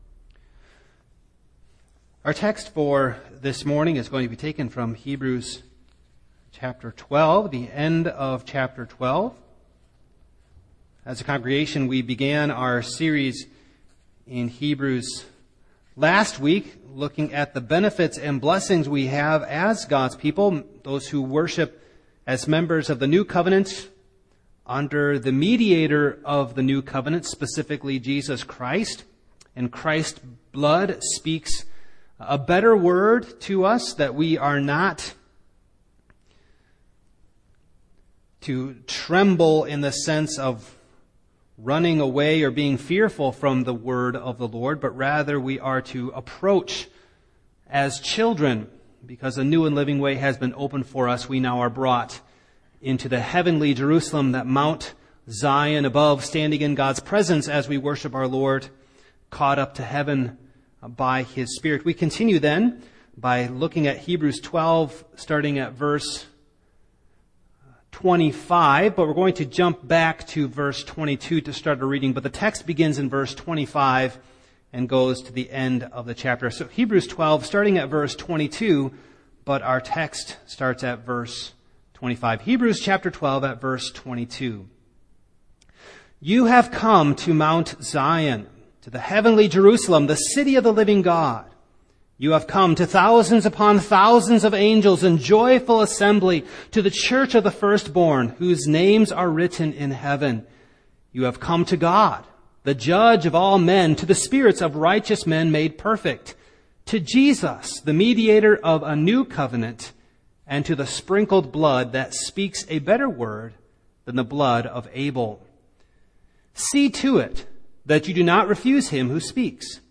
Passage: Heb. 12: 25-29 Service Type: Morning